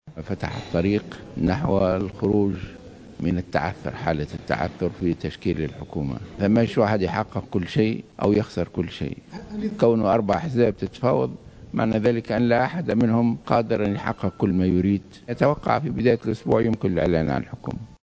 Dans une déclaration rapportée par Jawhara Fm, à l’issue d’une entrevue à Dar Dhiafa avec le candidat à la primature Habib Jamli, Rached Ghannouchi a assuré que la situation de blocage est en passe d’être résolue.